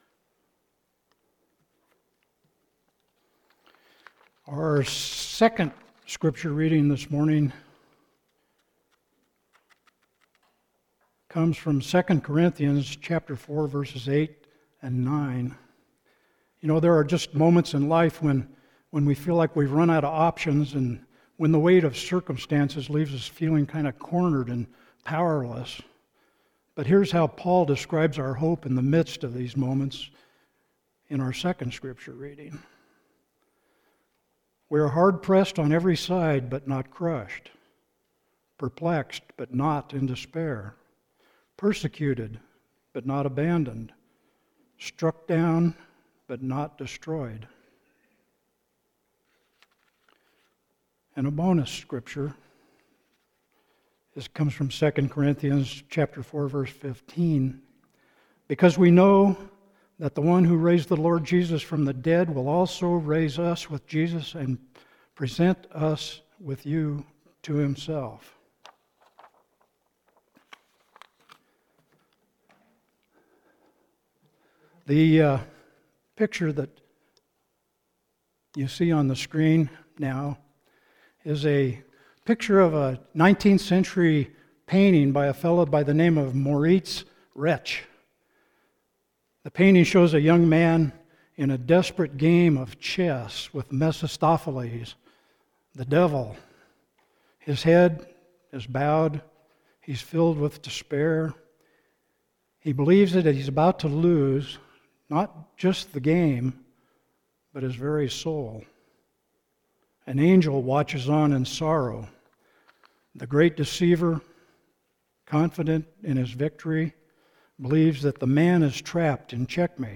Sermon – September 7, 2025 – “One More Move” – First Christian Church